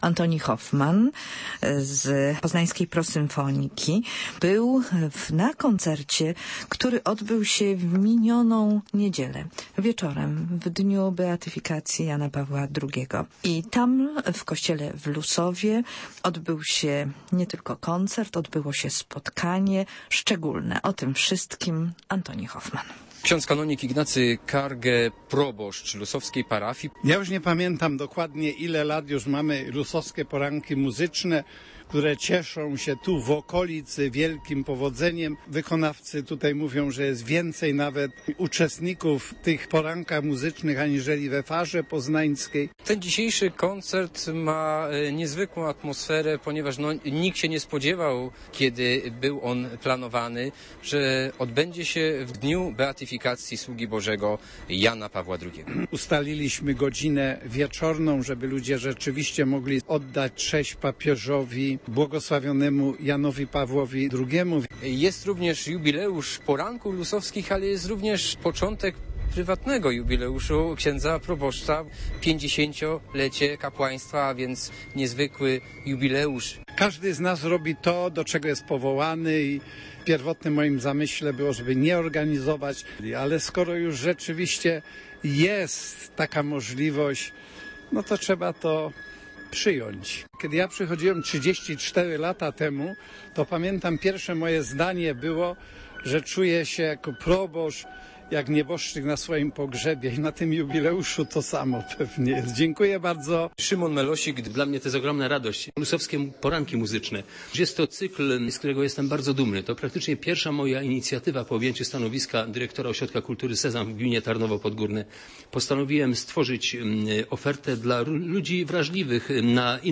Koncert na beatyfikację